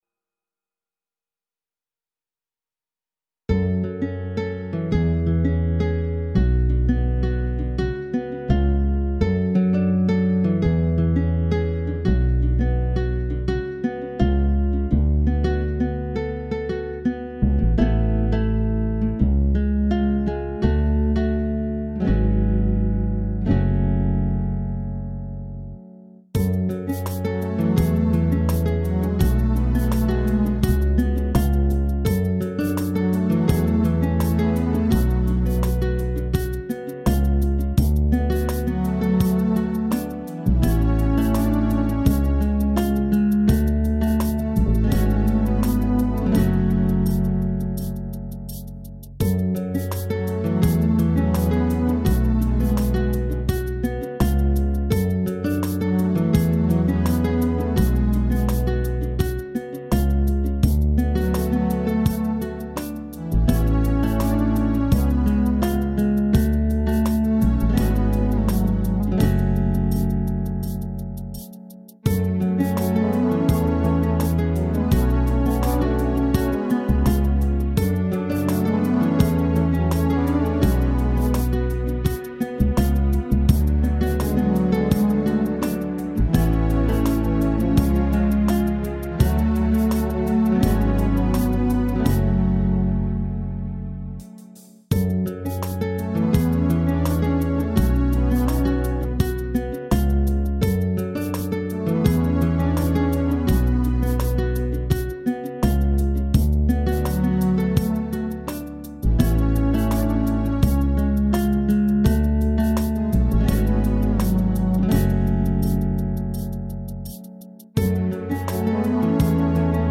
Rock Ballads